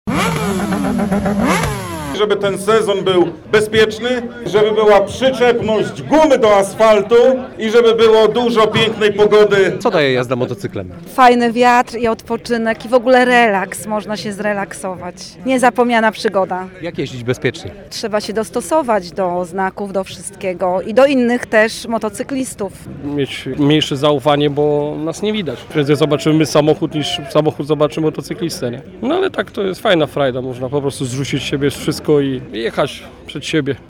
Na miejscu był nasz reporter.